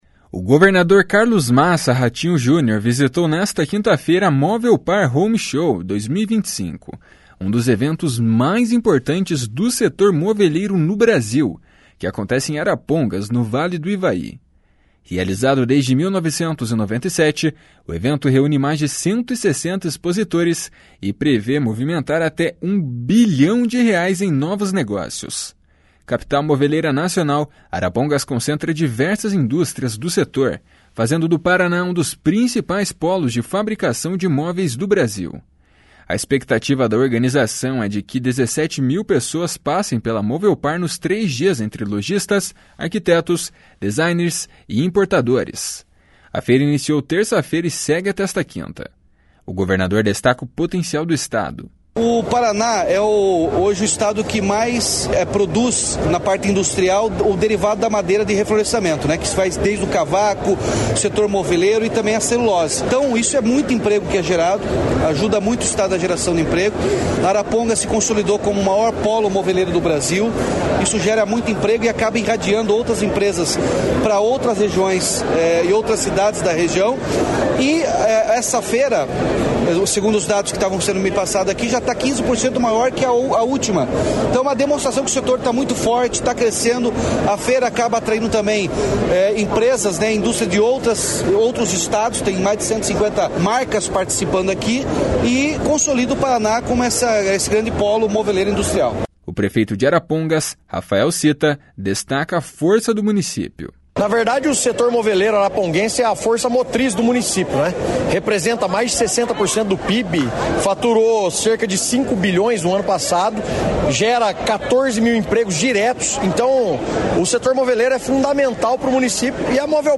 O governador destaca o potencial do Estado. // SONORA RATINHO JUNIOR //
O prefeito de Arapongas, Rafael Cita, destaca a força do município.
O secretário estadual da Indústria, Comércio e Serviços, Ricardo Barros, ressaltou como os polos regionais, como Arapongas, ajudam no crescimento da economia do Estado.